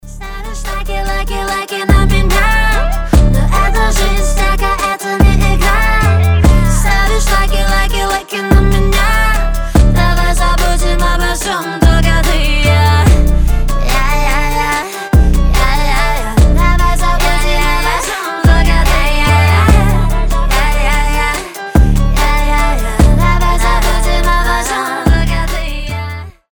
• Качество: 320, Stereo
ритмичные
басы
озорные